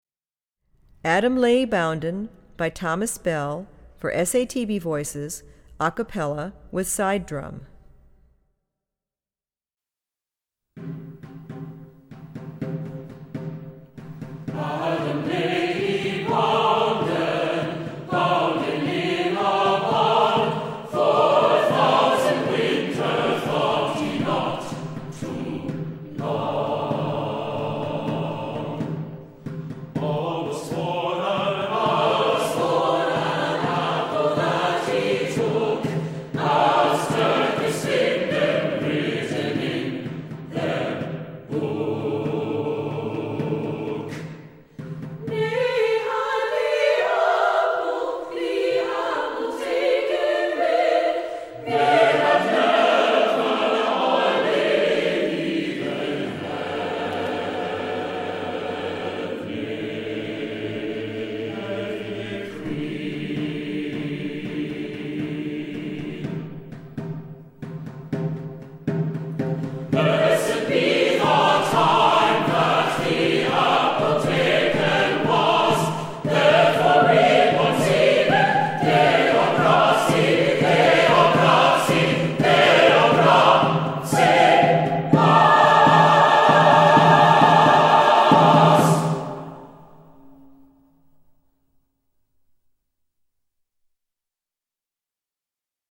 Voicing: SSATB